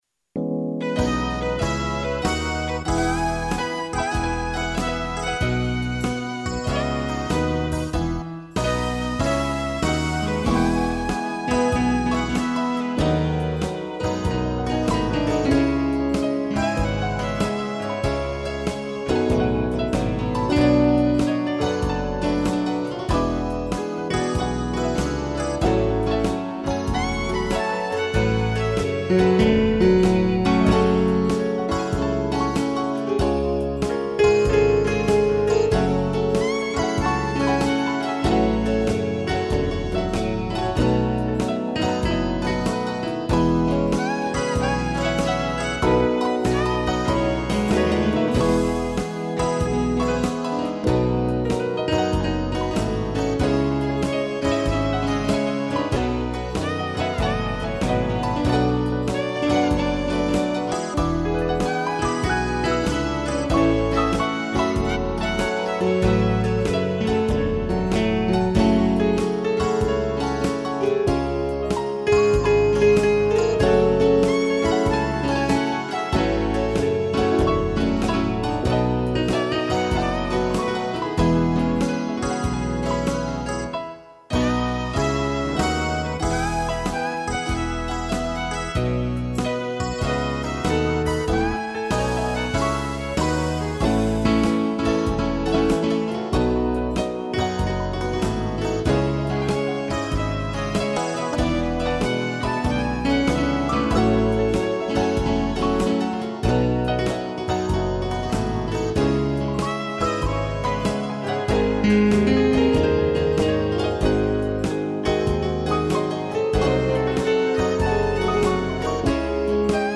piano e violino
(instrumental)